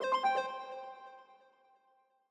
Longhorn Valley - Notify Messaging.wav